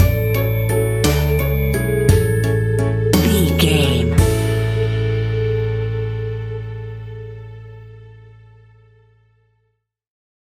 Ionian/Major
childrens music
instrumentals
childlike
cute
happy
kids piano